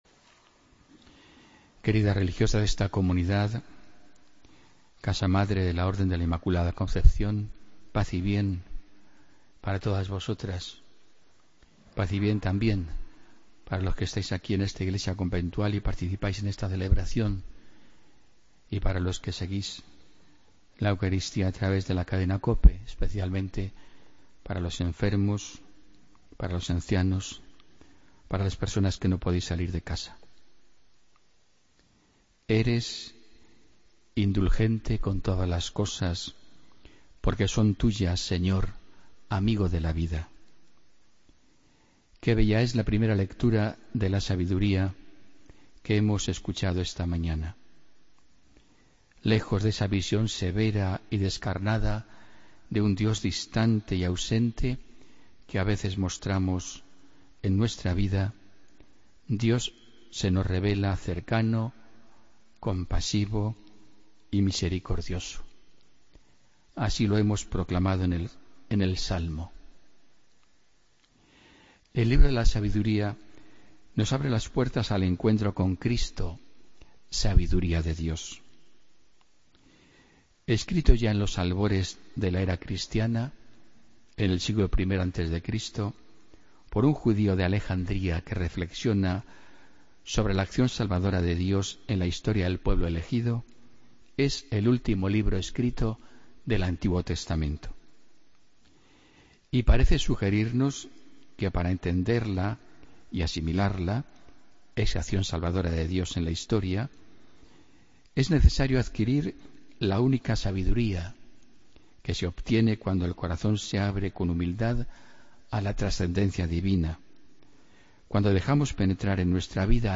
AUDIO: Homilía del domingo 30 de octubre de 2016